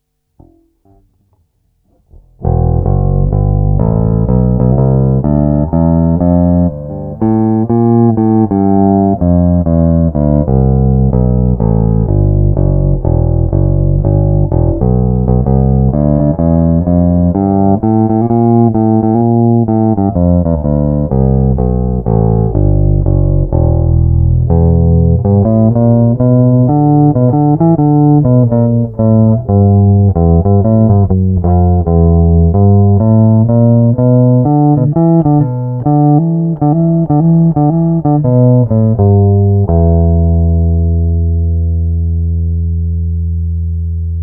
Audiovox 736 replica, the first solid body bassguitar!